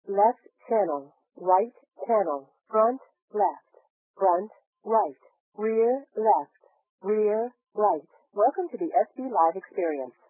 Superposition of frequencies above 2000 Hz and below 3000 Hz with compression ratio=1.5 and offset=-500 Hz
(composition width=1000 Hz)
After compression and decompression
by the VLC 8 codec at 12250 bps